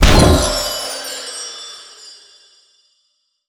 spell_harness_magic_07.wav